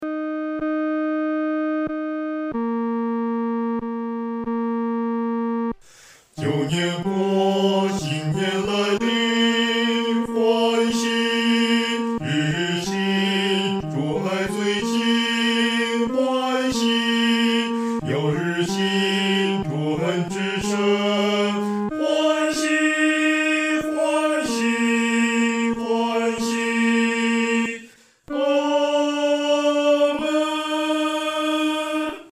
男高
本首圣诗由网上圣诗班录制